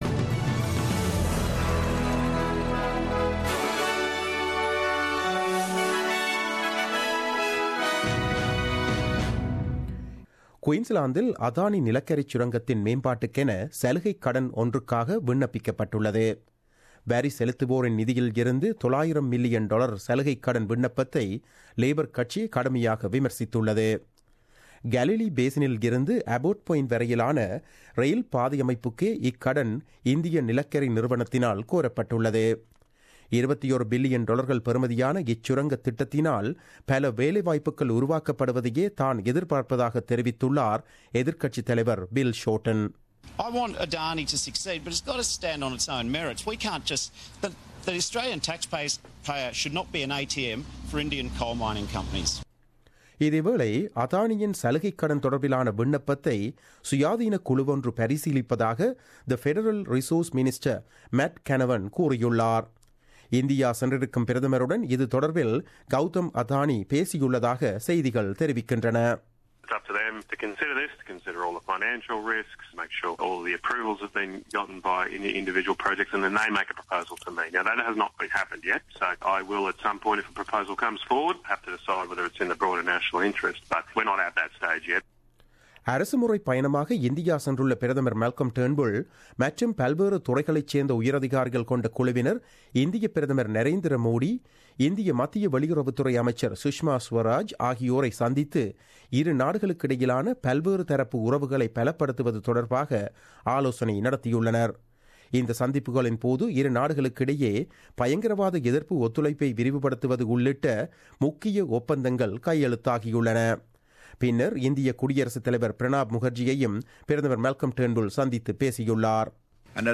The news bulletin aired on Wednesday 12 April 2017 at 8pm.